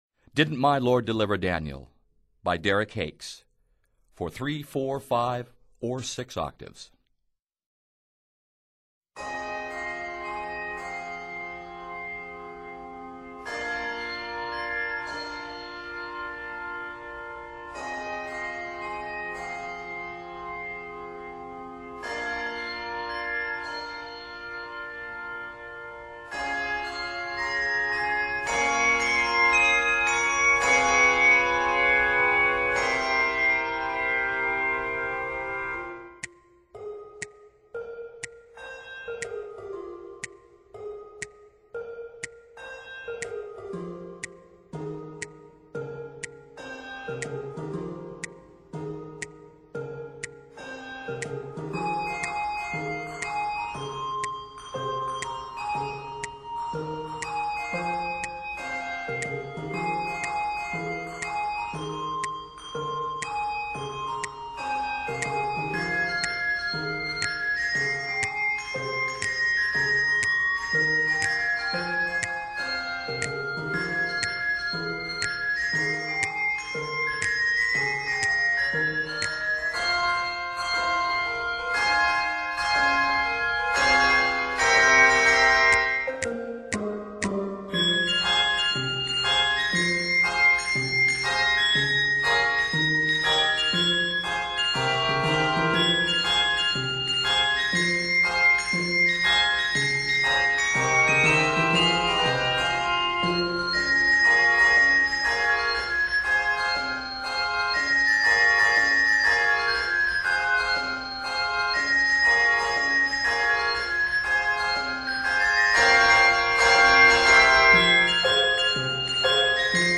A peppy setting with a toe-tapping beat
is scored in f minor